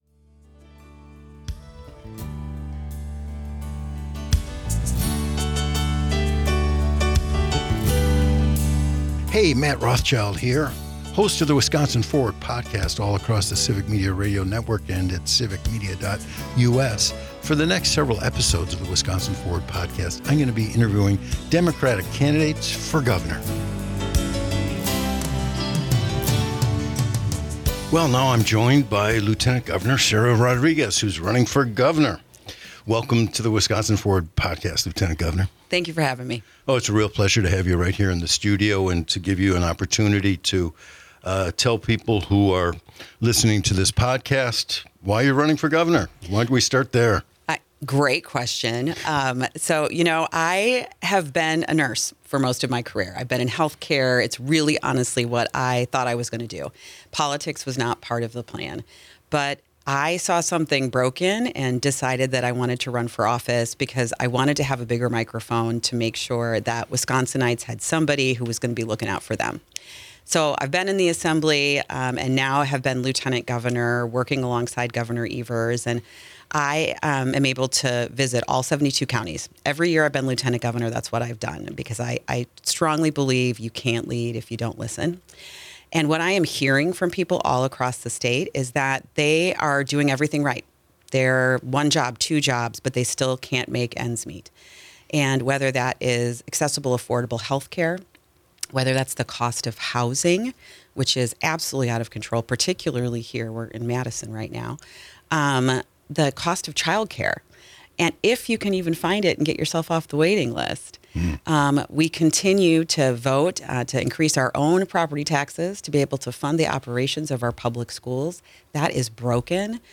An Interview with Sara Rodriguez - Civic Media